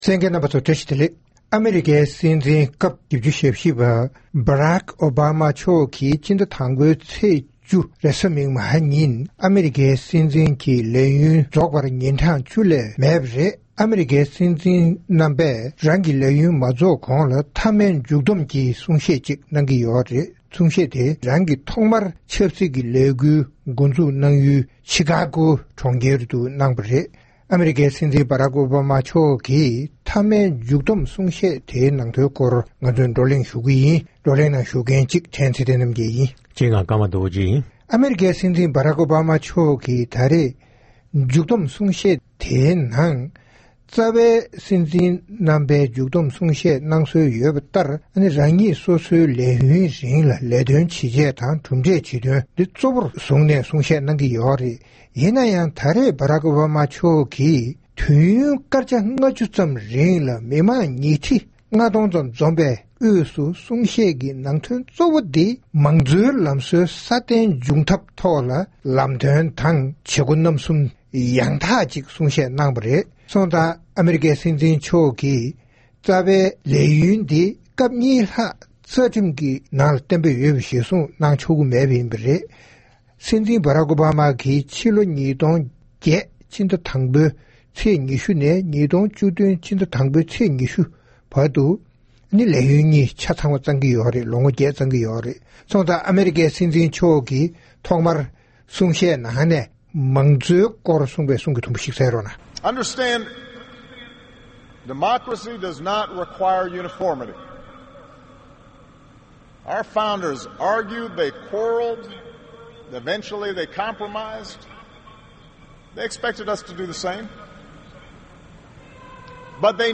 ༄༅། །ཐེངས་འདིའི་རྩོམ་སྒྲིག་པའི་གླེང་སྟེགས་ཞེས་པའི་ལེ་ཚན་ནང་། ཨ་མེ་རི་ཁའི་སྲིད་འཛིན་བ་རཀ་་ཨོ་བ་མ་Barack Obama མཆོག་གི་ལས་ཡུན་རྫོགས་མཚམས་ཀྱི་མཐའ་བསྡོམས་གསུང་བཤད་ནང་། ལས་དོན་ཇི་བྱས་དང་གྲུབ་འབྲས་ཇི་ཐོན་མདོར་བསྡུས་དང་། མང་གཙོའི་ལམ་སྲོལ་གྱི་གཙོ་གནད་དང་གཅེས་སྐྱོང་བྱ་ཕྱོགས་གཙོ་བོར་གསུངས་པའི་གཙོ་གནད་ཁག་ཅིག་ཟུར་འདོན་བྱས་ཏེ་རྩོམ་སྒྲིག་འགན་འཛིན་རྣམ་པས་འགྲེལ་བརྗོད་གནང་བ་ཞིག་གསན་རོགས་གནང་།